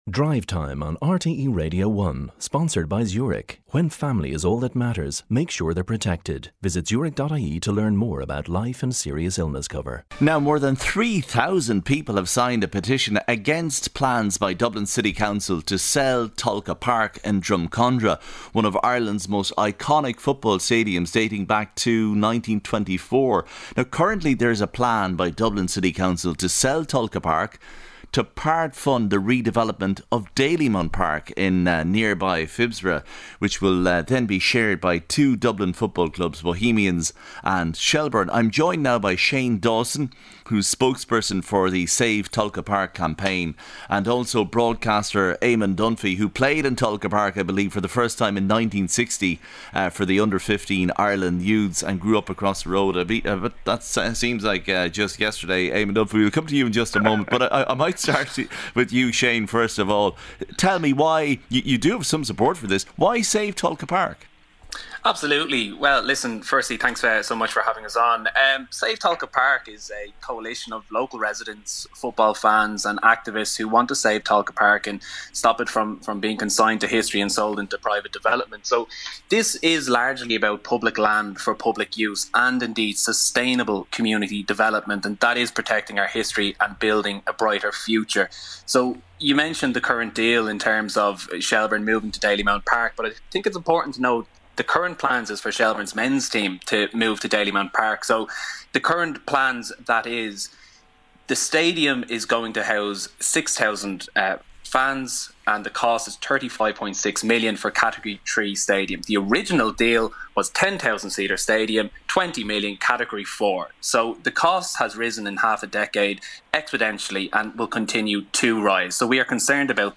7:35am Sports News - 11.06.2021